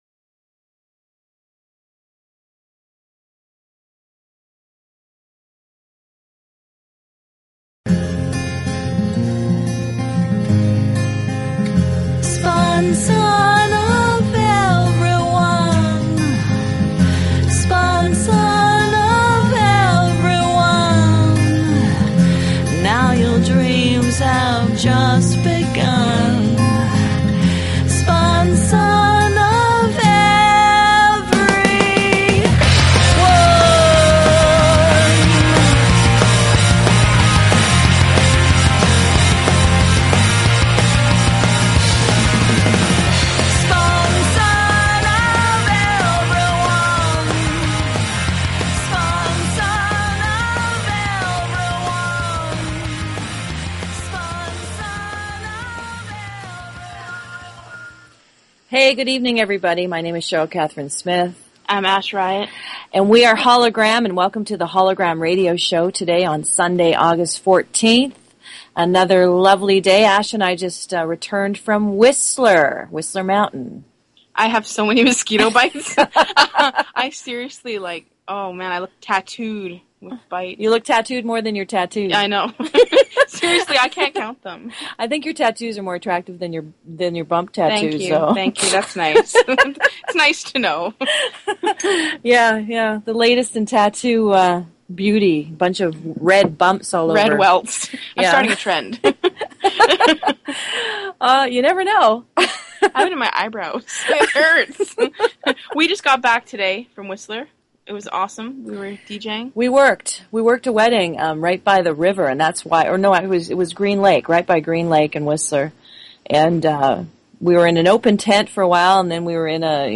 Talk Show Episode, Audio Podcast, Hollow-Gram_Radio_Show and Courtesy of BBS Radio on , show guests , about , categorized as
They offer a unique radio show where they share their music, their sharp wit, and their visions for This planet.